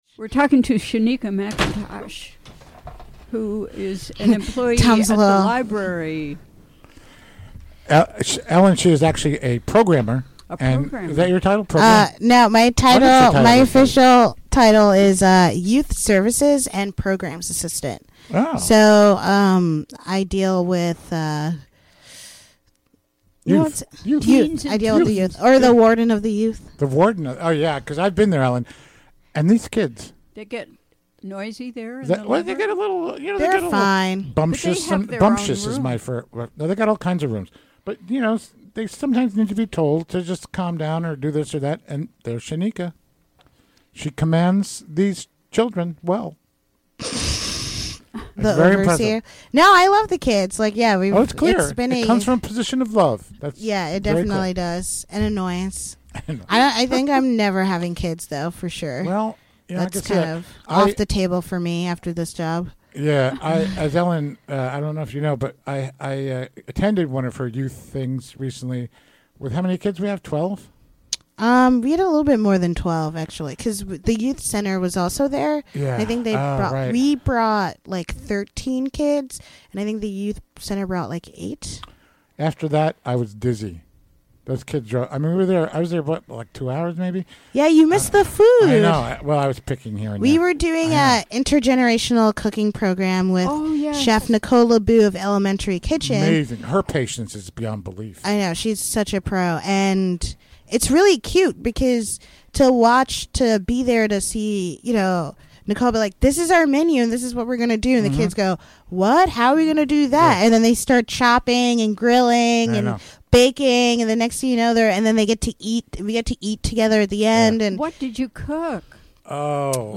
Recorded live during the WGXC Afternoon Show, Thu., May 31.